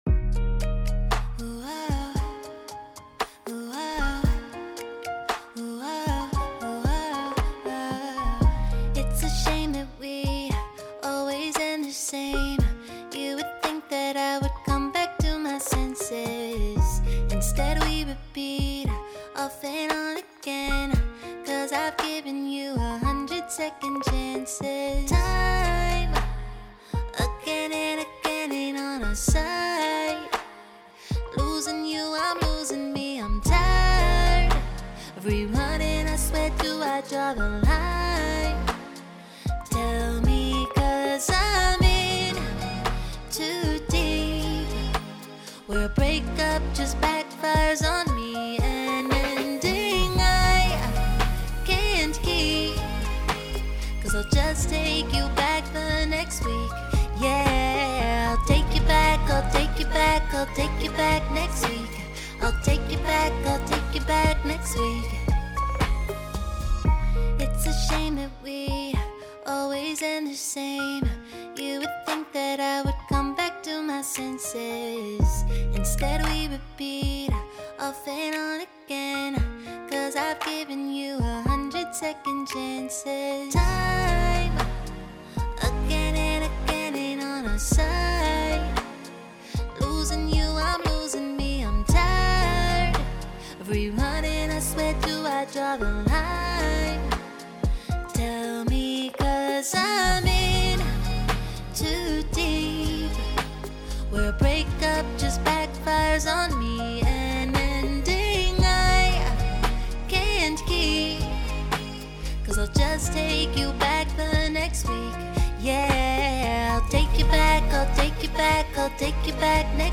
00s, R&B, Pop
Bb Minor